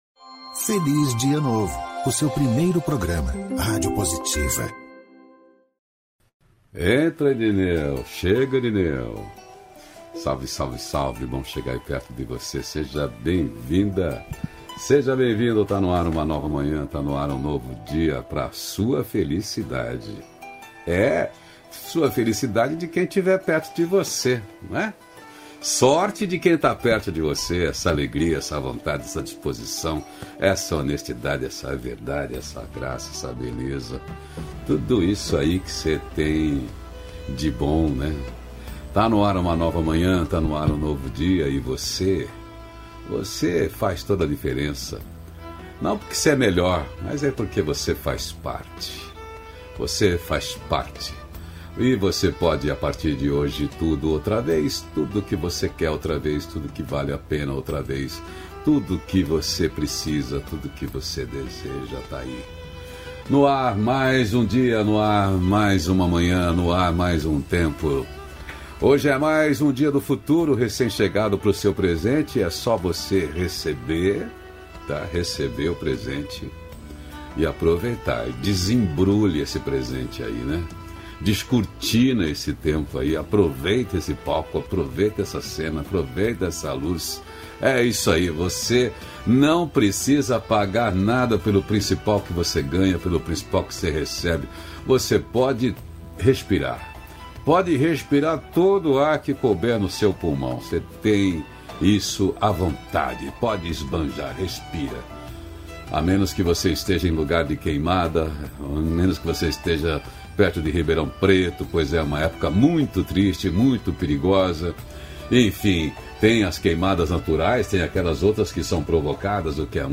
Diálogo nutritivo
ao vivo